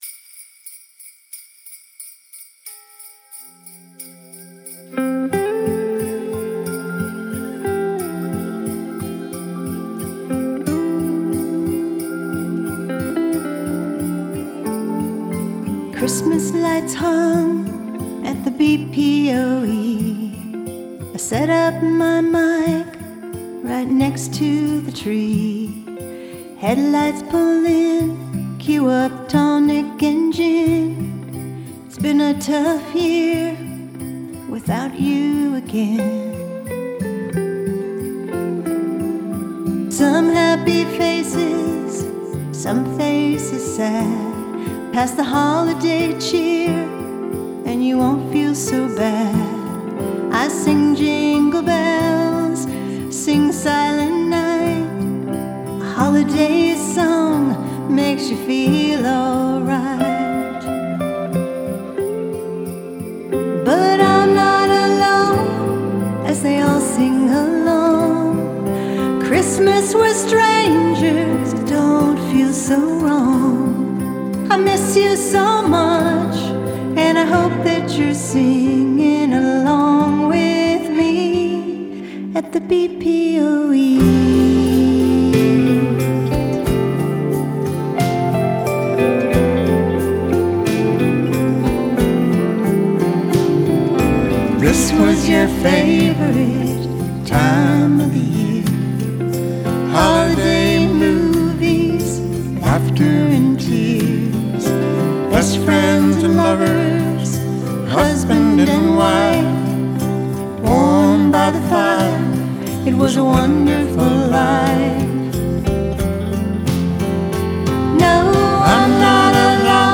guitars, bass, drums, keyboards